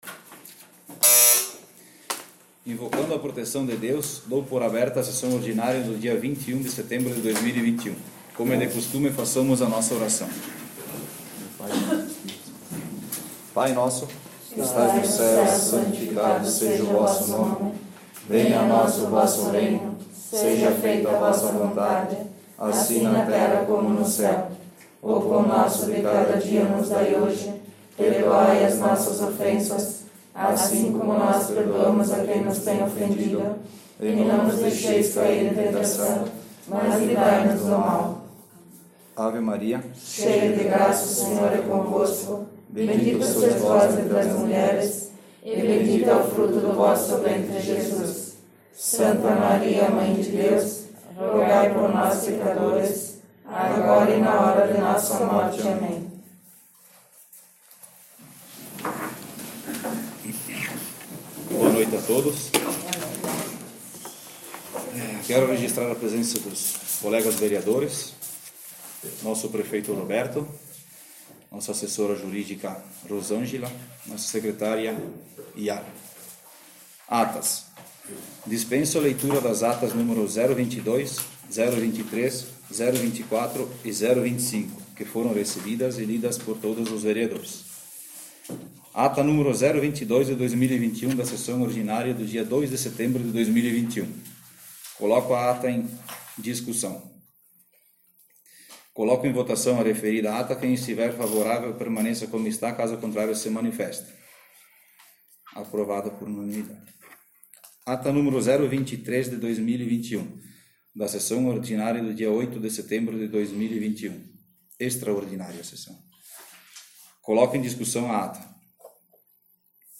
Sessão Ordinária 21/09/2021